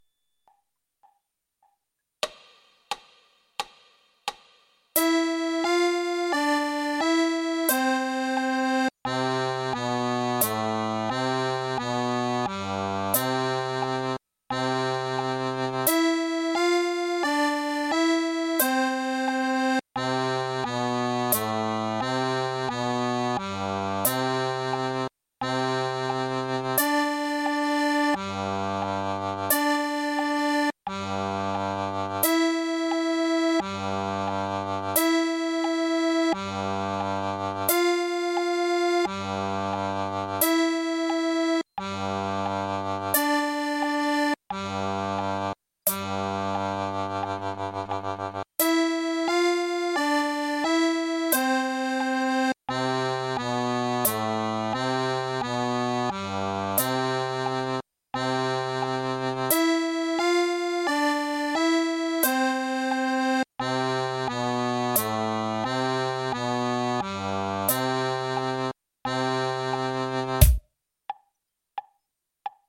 scala Misolidia